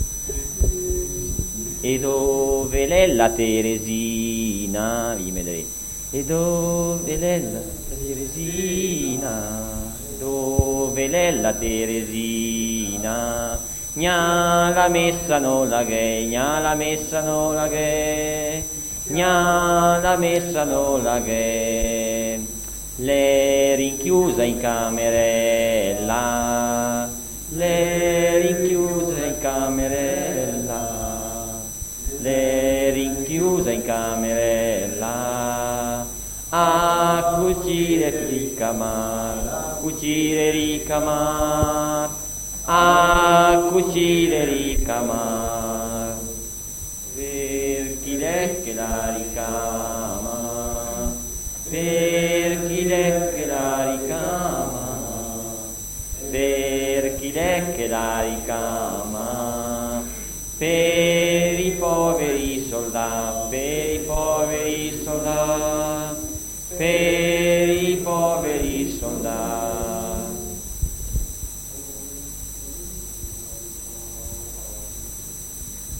Registrazioni di canti popolari effettuate presso la sede del Coro Genzianella di Condino. 11 settembre 1972. 1 bobina di nastro magnetico.